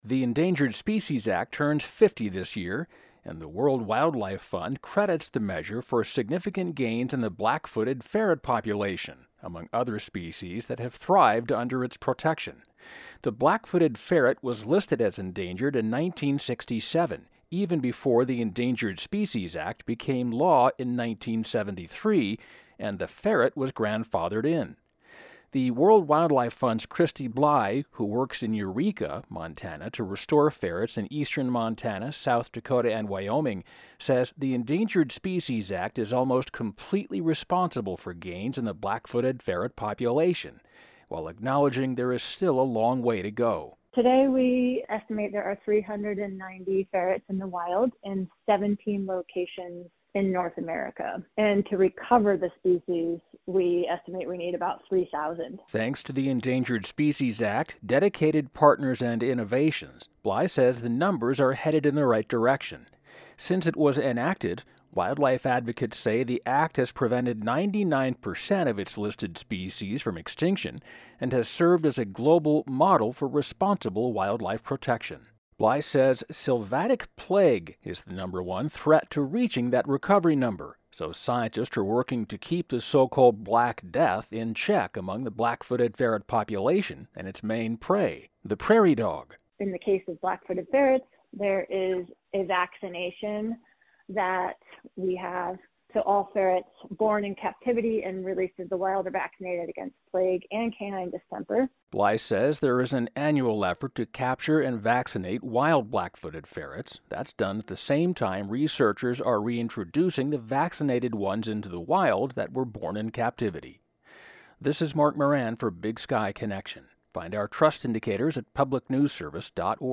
Pronouncer: sylvatic (sil VAT' ik)